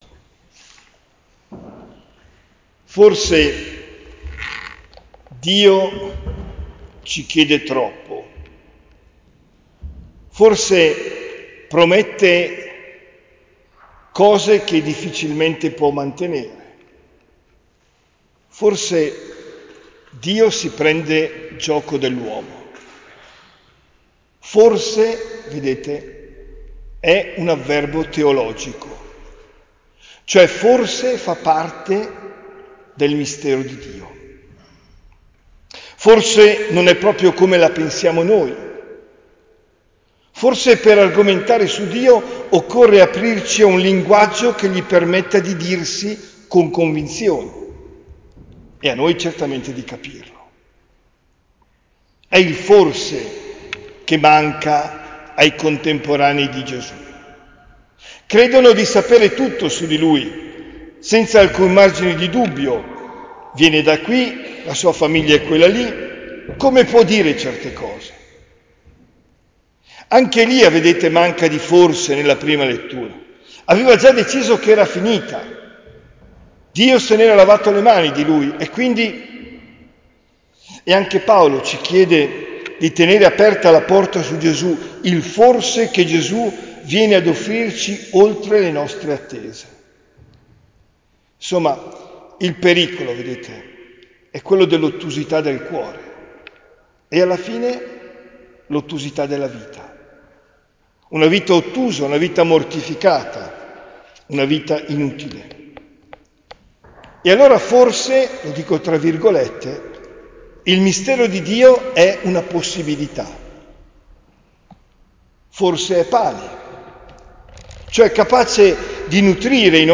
OMELIA DELL’11 AGOSTO 2024